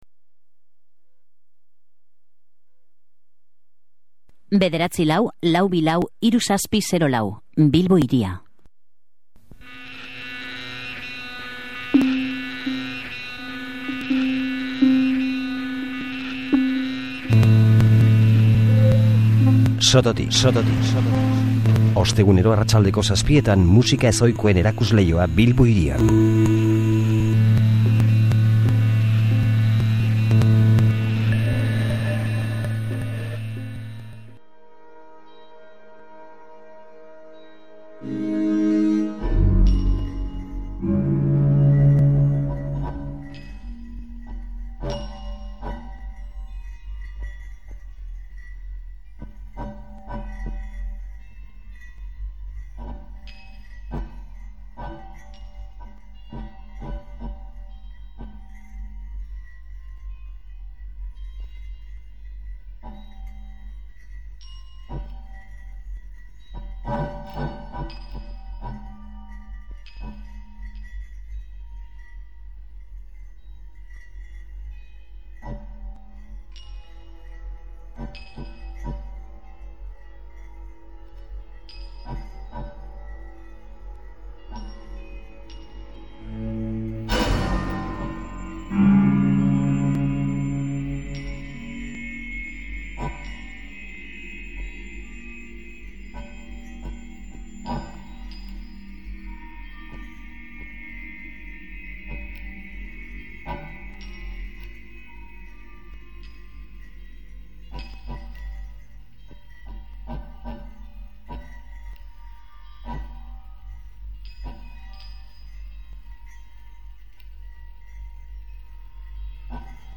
“Soinu-povera” egiten du, bere esanetan.